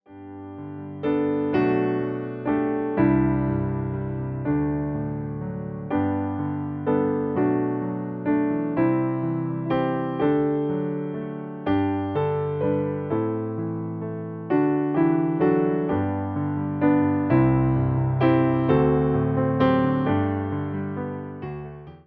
Podkład fortepianowy
Wersja demonstracyjna:
134 BPM
G – dur